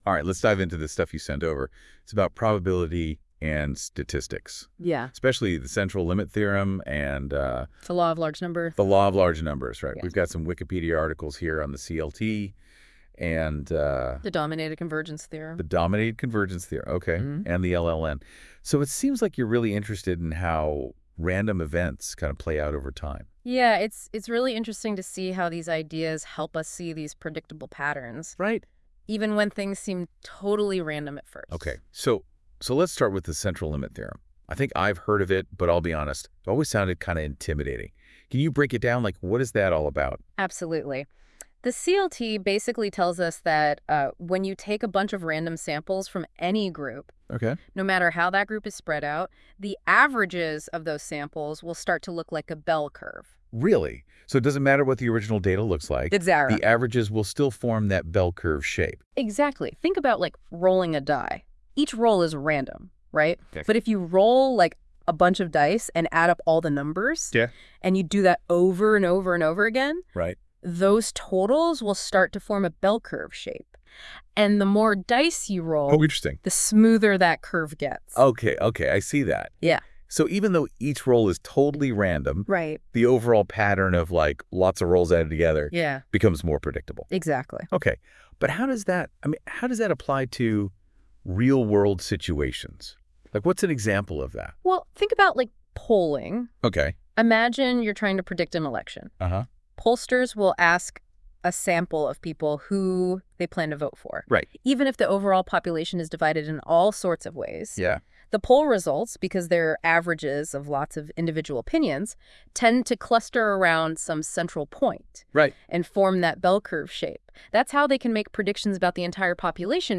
This podcast is generated by the content of the following wikipages using NotebookLM @ Google/Germini